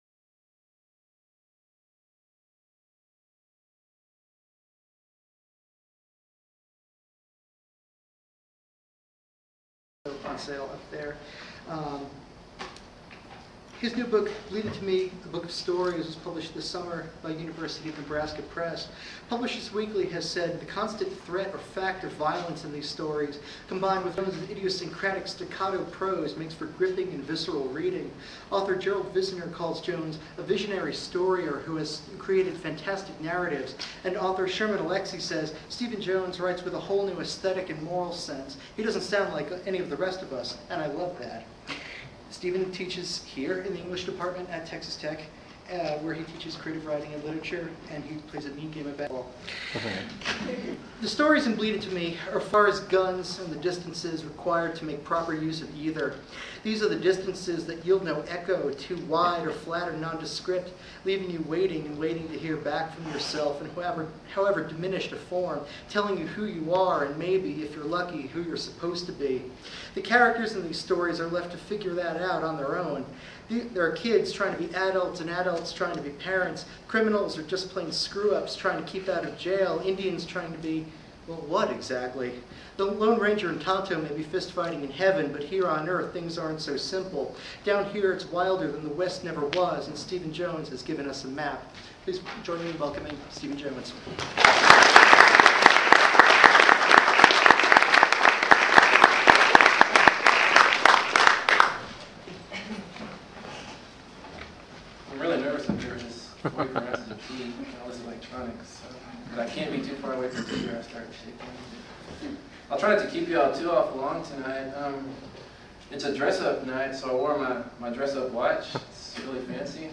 Lubbock / Texas Tech reading footage - Stephen Graham Jones